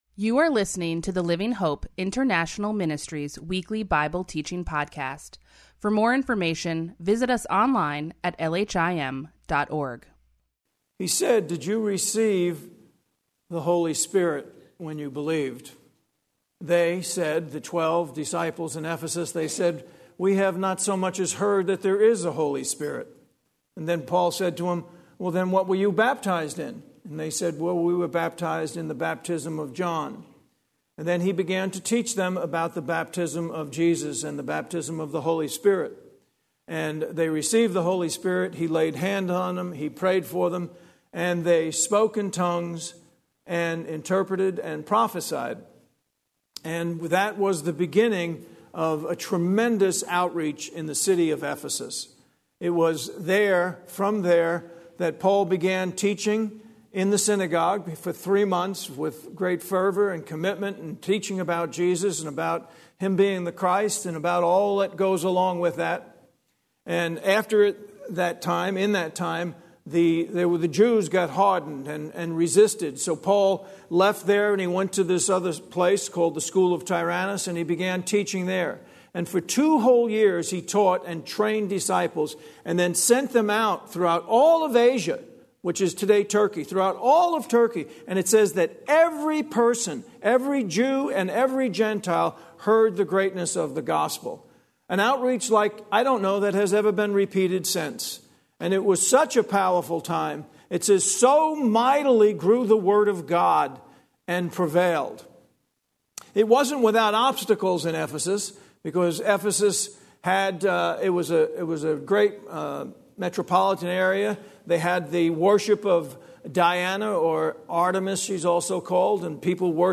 Weekly Bible Teaching